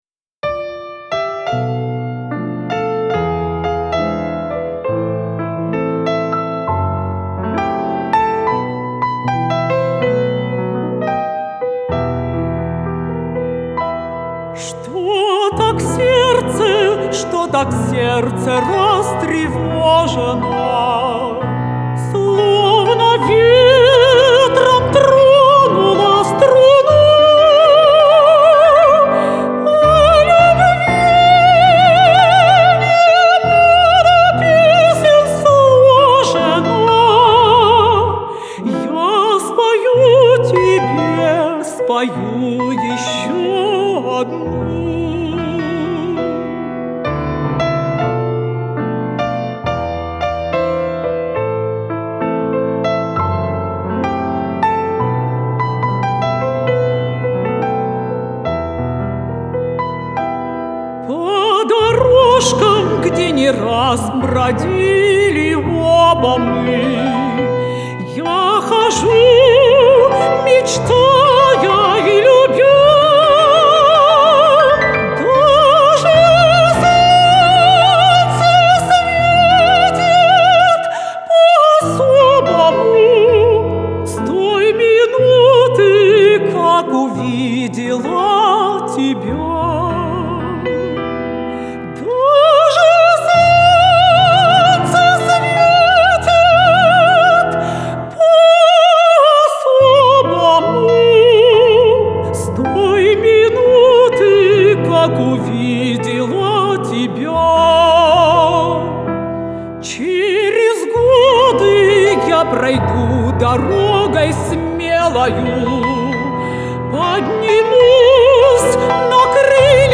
Музыкальное творчество
Душевный романс.